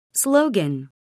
미국 [slóugən]